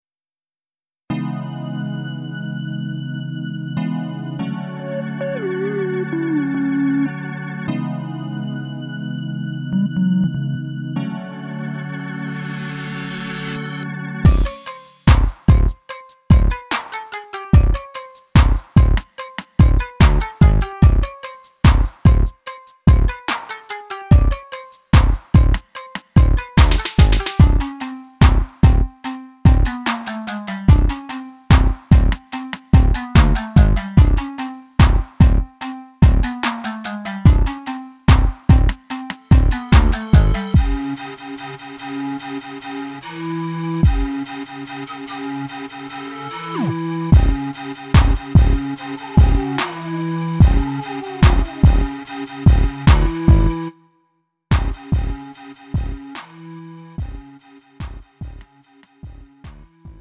음정 -1키 2:54
장르 가요 구분 Lite MR
Lite MR은 저렴한 가격에 간단한 연습이나 취미용으로 활용할 수 있는 가벼운 반주입니다.